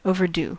Ääntäminen
US : IPA : [ˌoʊ.vɚ.ˈdu]